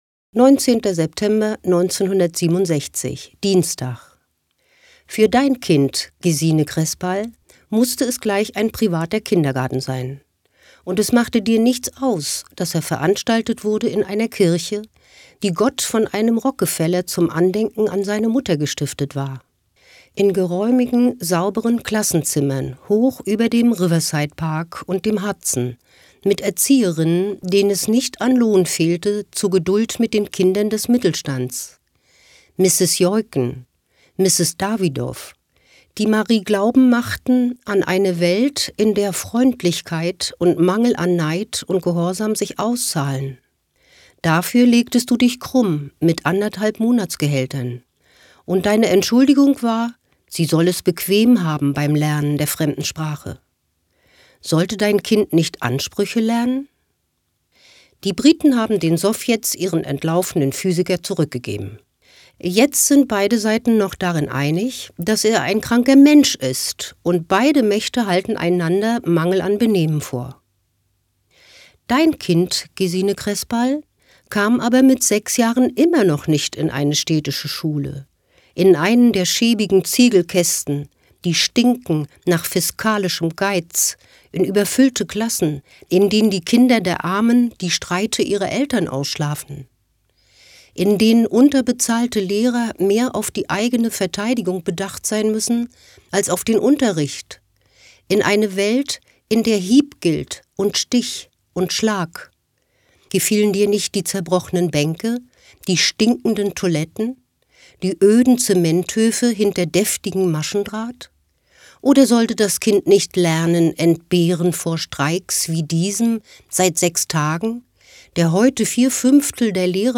Eine Stadt liest Uwe Johnsons Jahrestage - 19.